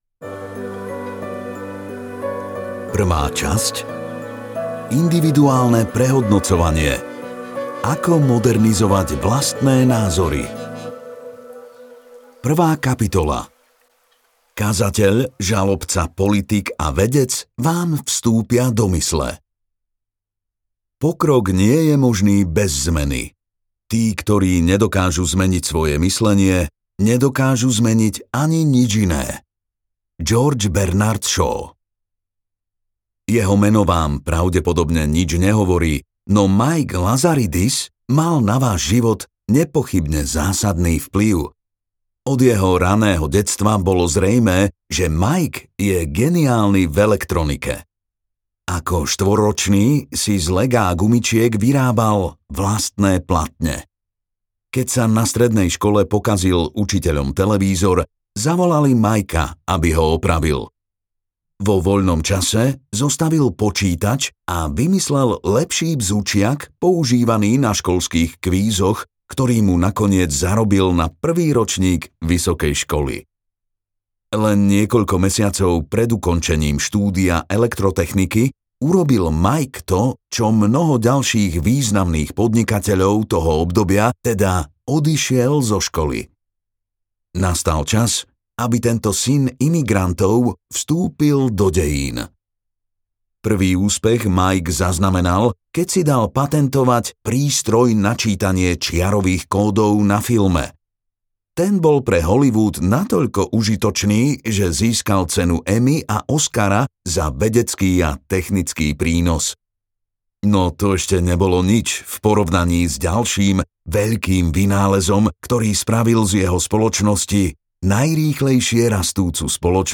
Ešte si to premysli je audiokniha o dôležitosti pochybovania a o tom, ako ľahšie spracovať nové fakty a nazerať na vlastné omyly pozitívnou optikou.